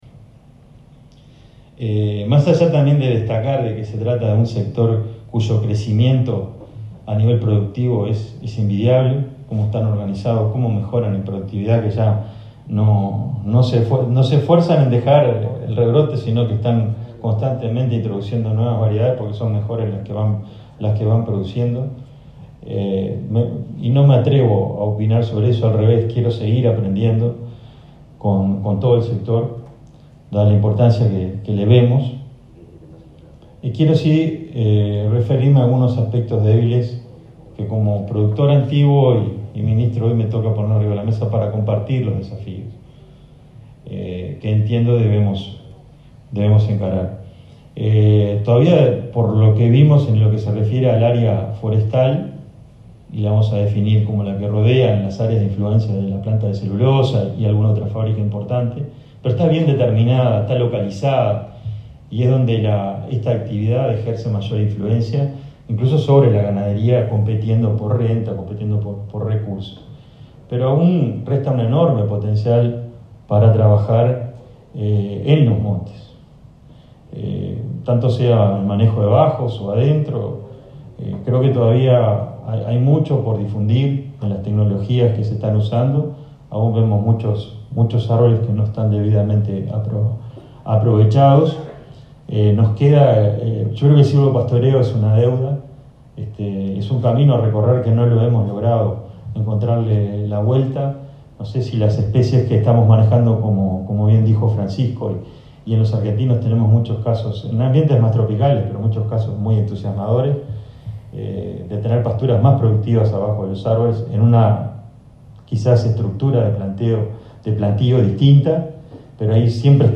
En el marco de un desayuno de trabajo de la Sociedad de Productores Forestales, el ministro de Ganadería, Carlos Uriarte, destacó que el sector tiene un enorme potencial para trabajar en los montes y un gran debe en el manejo y la vigilancia de estos.